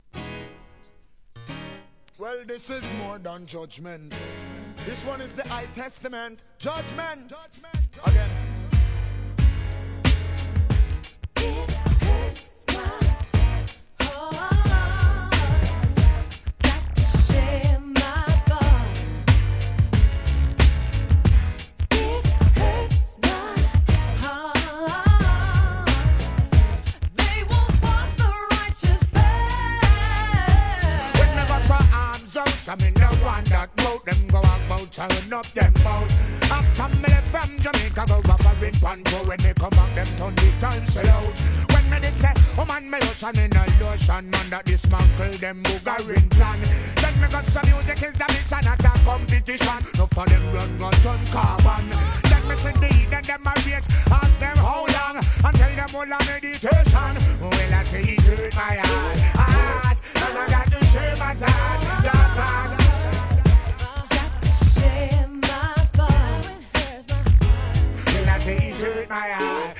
REGGAE
王道のR&B仕上げ!!